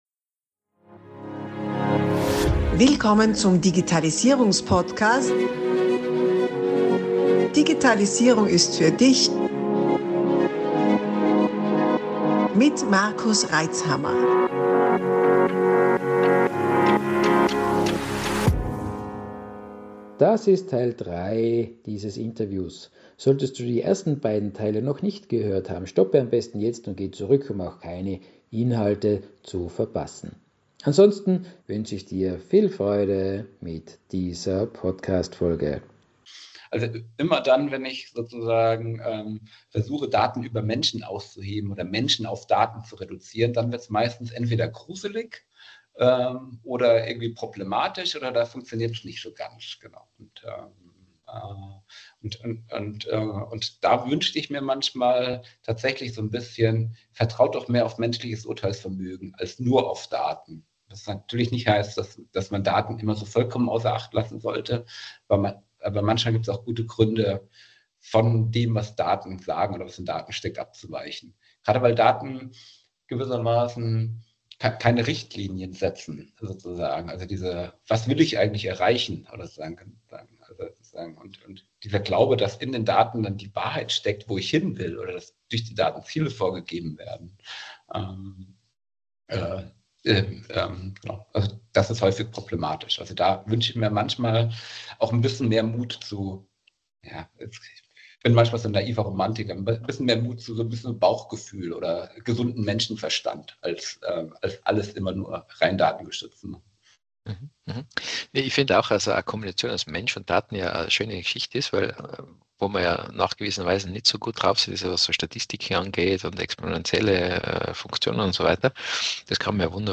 Spannende Interviews mit Unternehmern und Selbständigen zum Thema Digitalisierung. Gedanken und Denkanstöße zur Digitalisierung und zum konstru